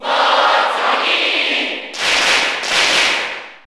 Category: Crowd cheers (SSBU)
Link_&_Toon_Link_Cheer_Italian_SSB4_SSBU.ogg